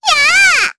Estelle-Vox_Attack8_jp.wav